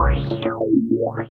50 MD PERC-R.wav